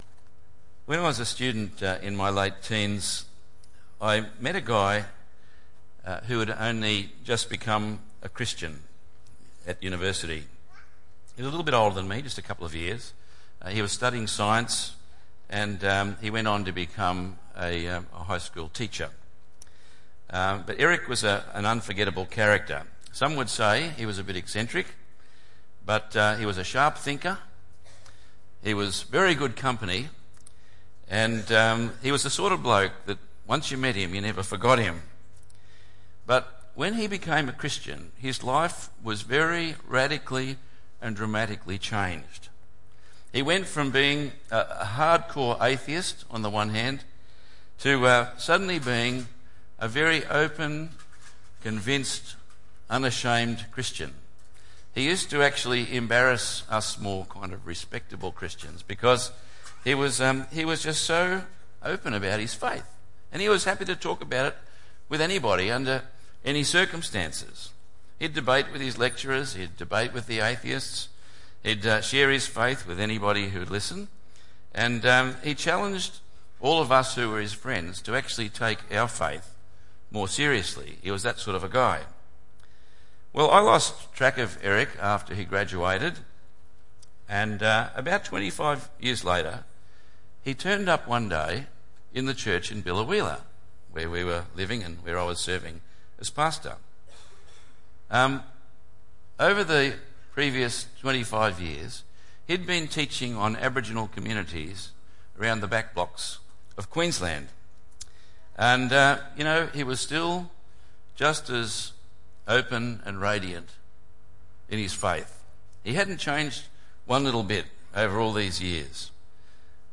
By the way, what difference has Christ made in your life? 2 Corinthians 5:11-6:2 Tagged with Sunday Morning